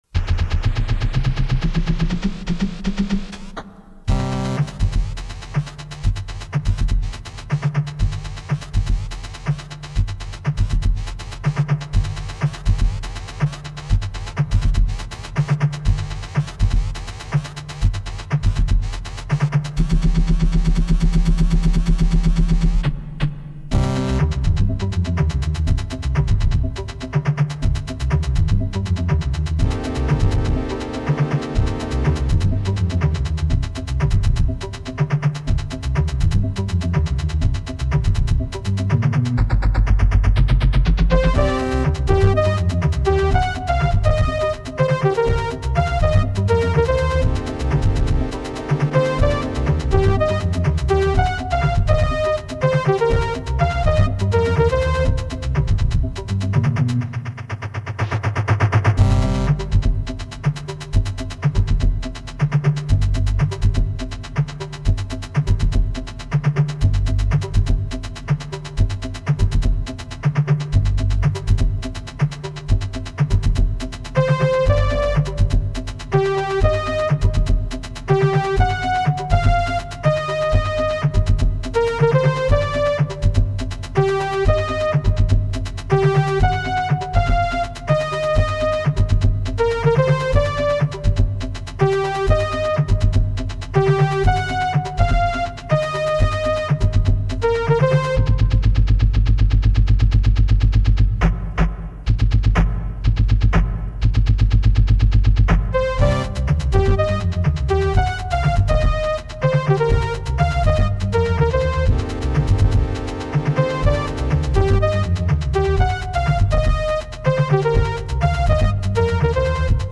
3050K mp3) I attempted to imitate 'record-scratching'
with the IMS.